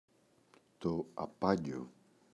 απάγκιο, το [a’panɟo]